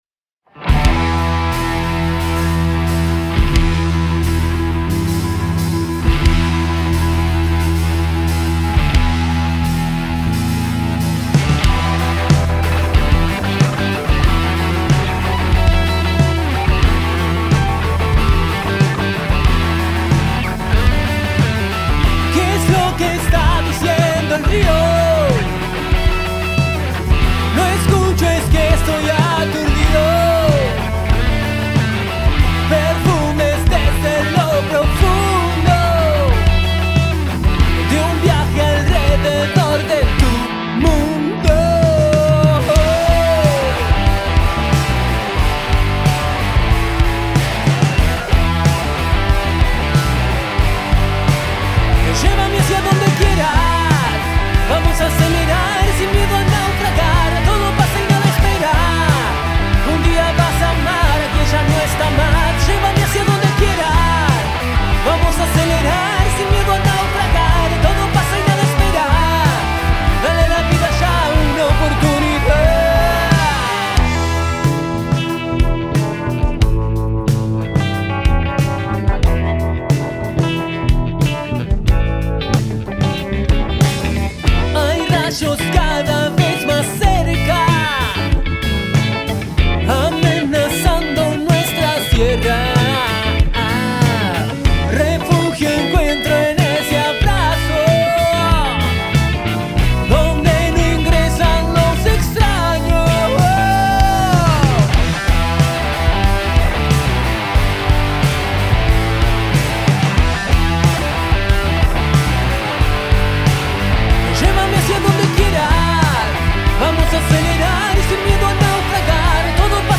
Grabada en vivo el 1 de junio de 2025
en Estudio del Monte